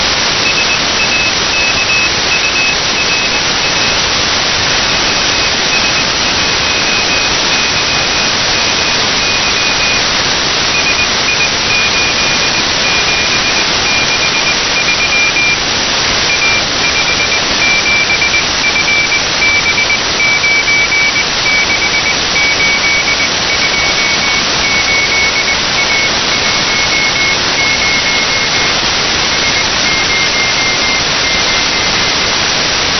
received the CW signal of SAKURA